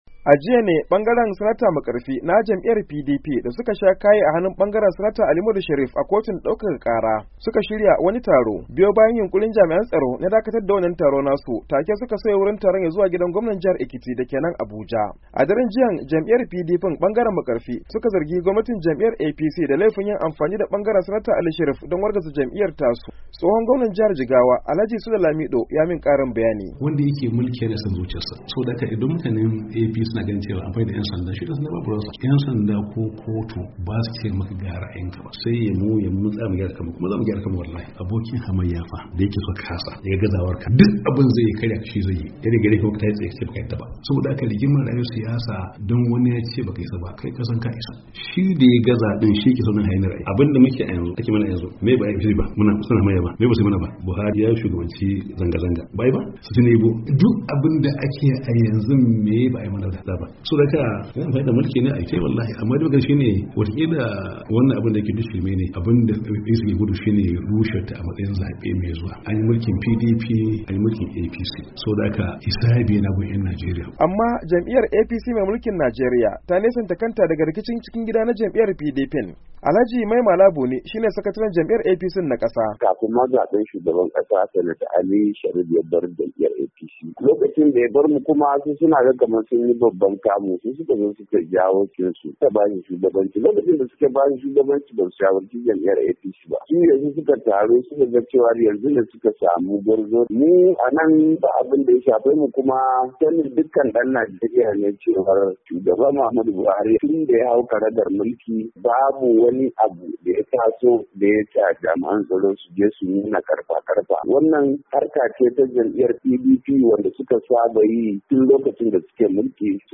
ABUJA: Taron PDP bangaren Sanata Ahmed Makarfi